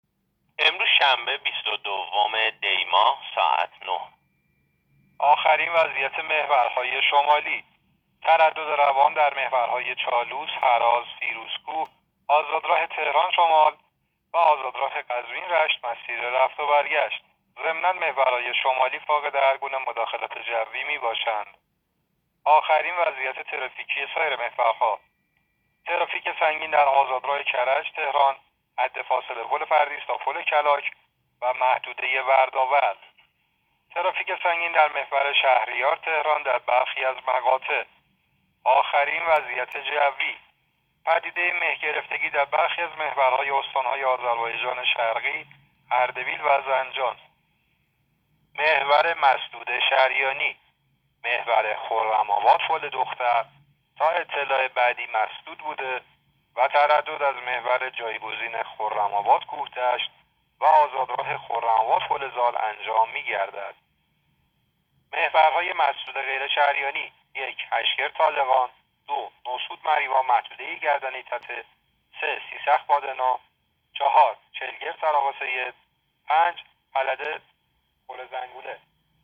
گزارش رادیو اینترنتی از آخرین وضعیت ترافیکی جاده‌ها تا ساعت ۹ بیست‌ودوم دی؛